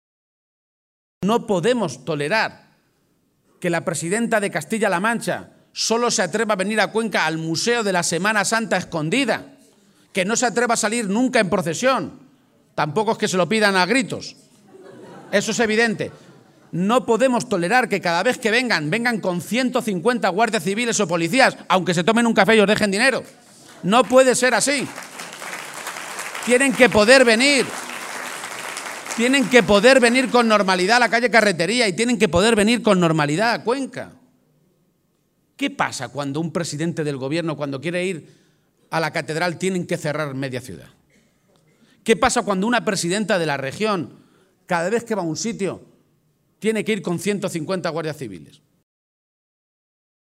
El secretario general del PSOE de Castilla-La Mancha. Emiliano García-Page, ha protagonizado hoy por la tarde en Cuenca el primero de los dos mítines con los que cierra la campaña para las elecciones europeas en Castilla-La Mancha.